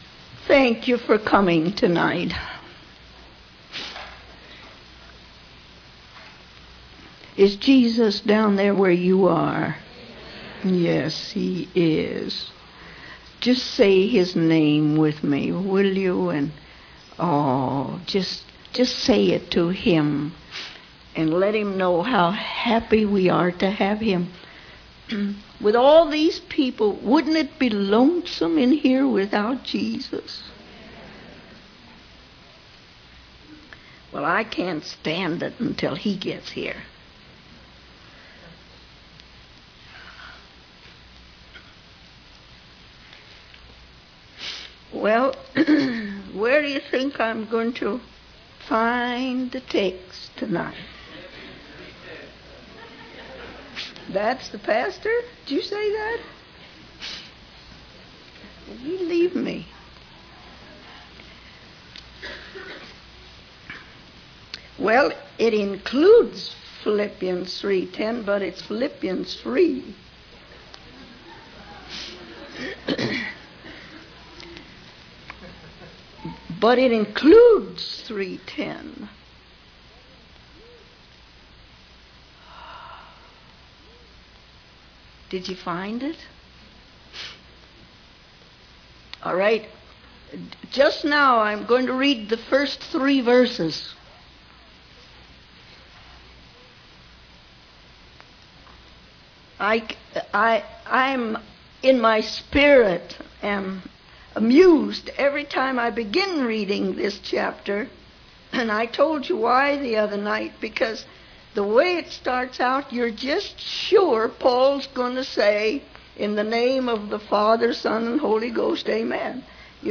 In this sermon, the preacher begins by expressing his amusement at the way the chapter starts, expecting a traditional ending but instead finding a heartfelt cry and prayer from Paul.